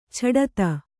♪ chaḍata